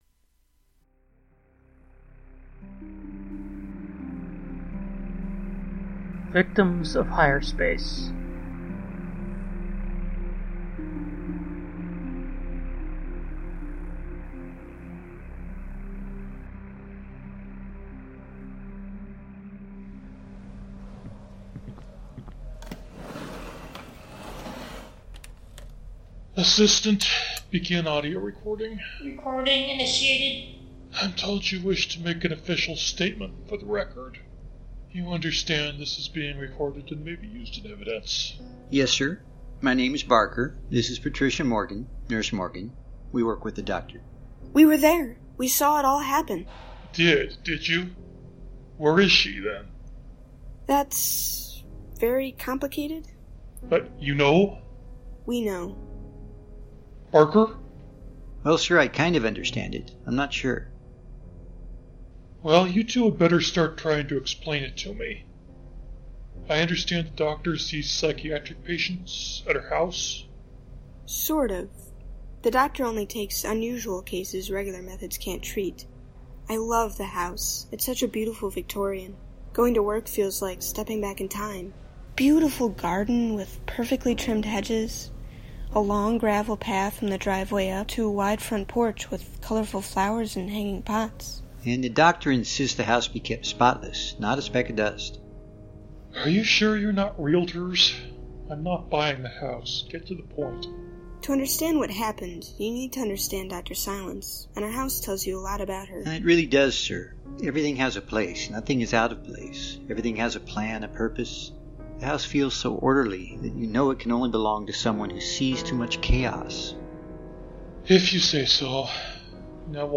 Victims of Higher Space (July 2020 - 38 minutes - Science Fiction) A strange new patient visits an unconventional psychiatric practice. Modernized version of an Algernon Blackwood story.